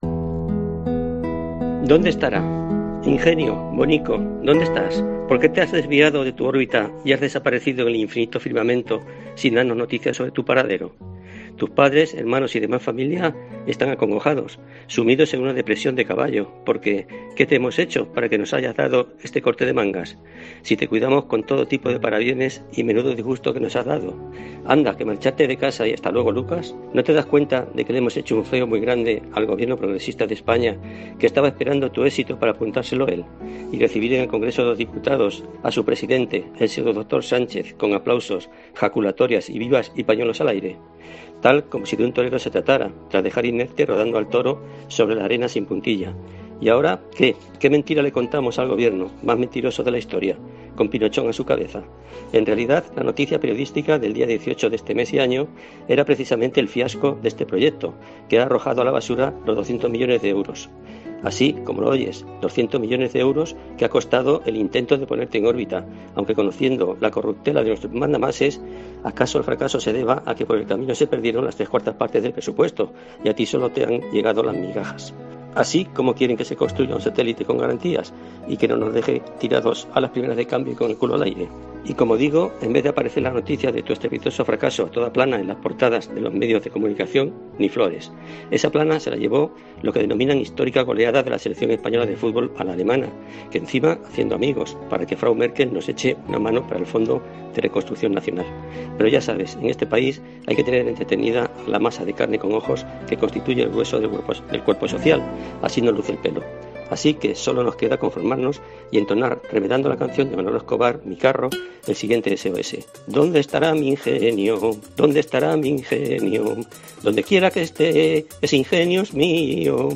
Opinión Albacete OPINIÓN ¿Donde se fue el cohete Ingenio?